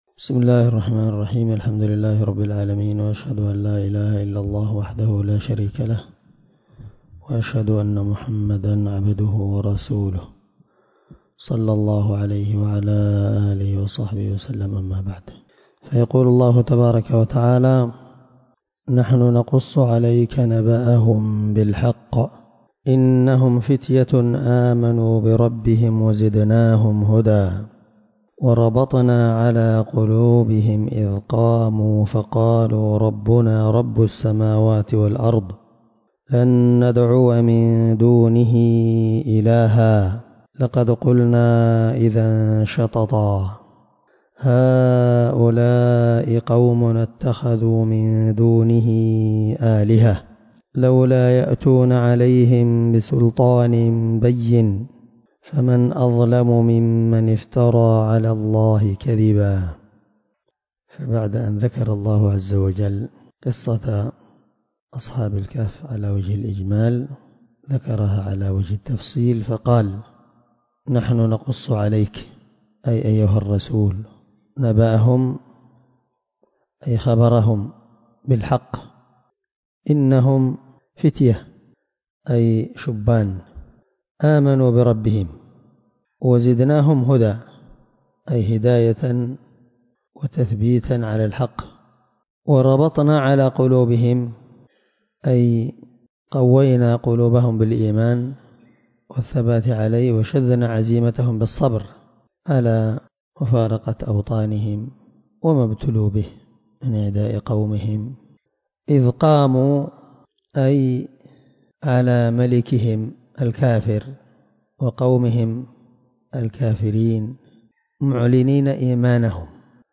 الدرس4 تفسير آية (13-15) من سورة الكهف